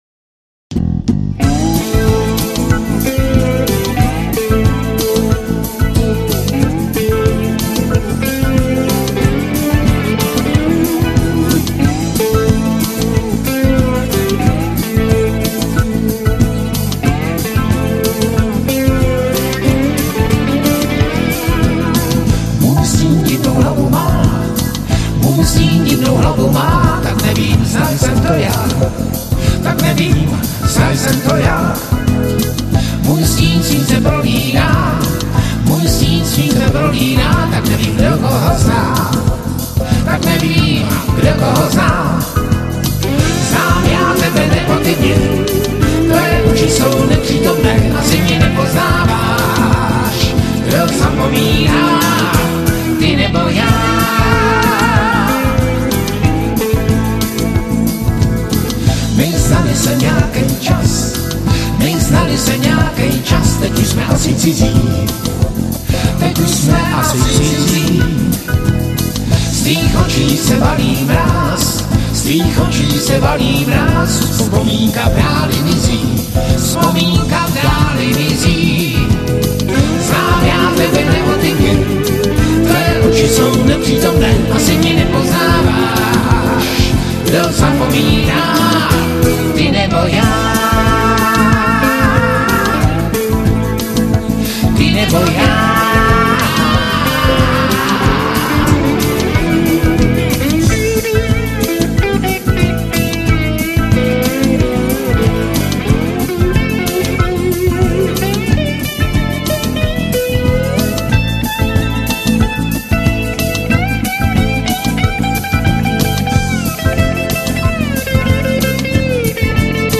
POHODA rock
Bicí
Klávesy
Sólova kytara